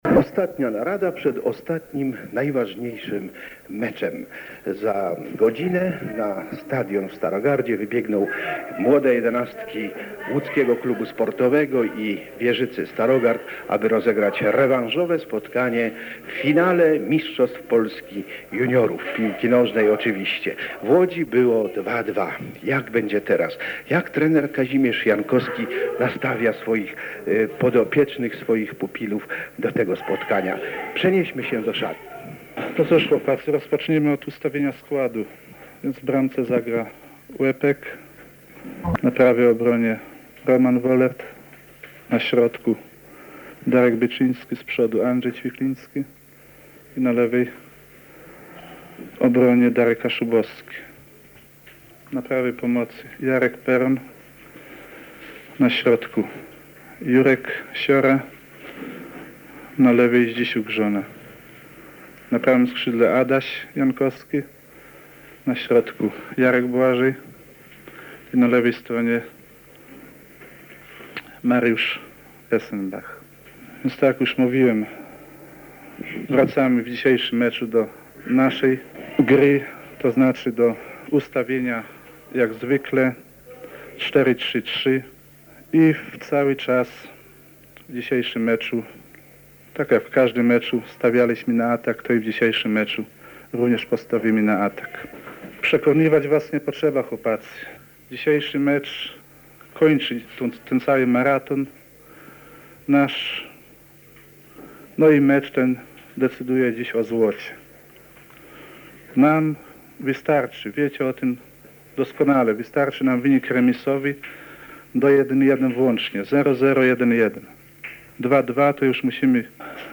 Udało nam się dotrzeć do archiwalnych materiałów radiowych zrealizowanych przez PR 1.
Reportaż drugiego spotkania finałowego Wierzyca – ŁKS, a także z szatni starogardzkiej drużyny podczas odprawy meczowej.